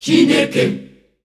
Index of /90_sSampleCDs/Zero G Creative Essentials Vol 27 Voices Of Africa WAV/Voices of Africa Samples/Track 05